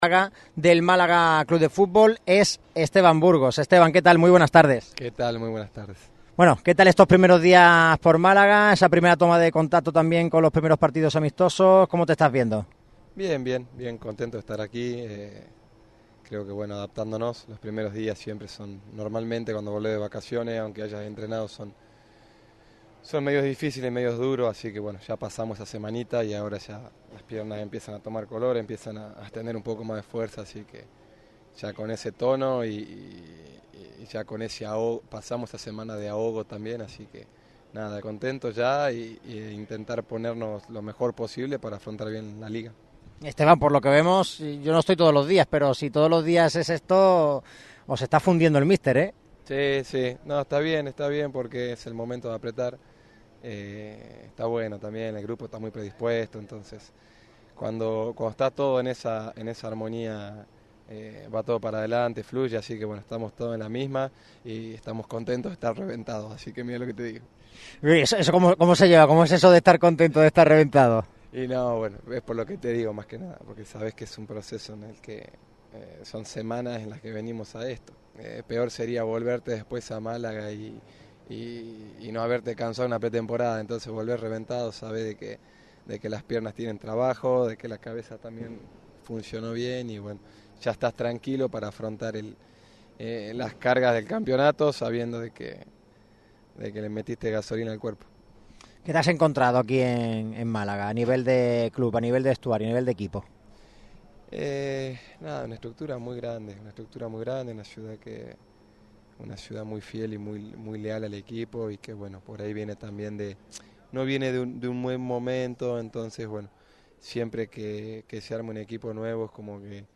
Esteban Burgos atendió en exclusiva al micrófono de Radio MARCA Málaga en plena concentración malaguista en el Atalaya Park.